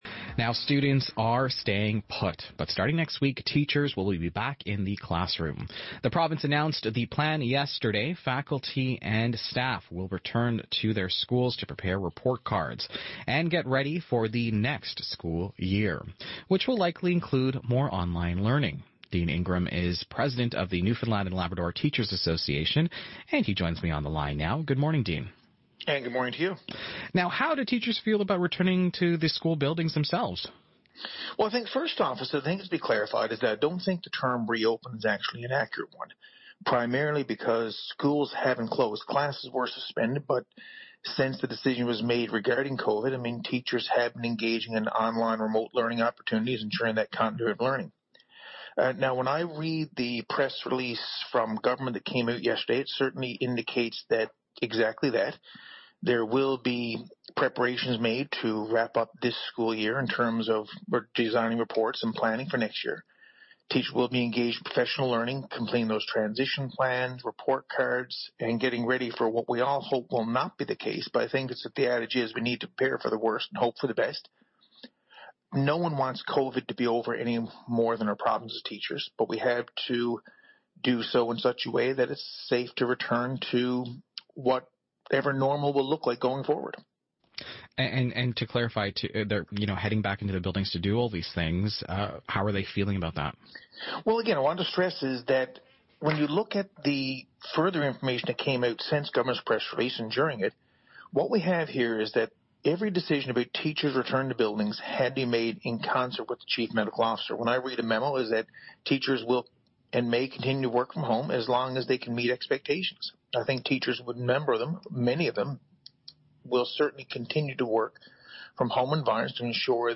Media Interview - CBC East Coast Morning Show - May 26, 2020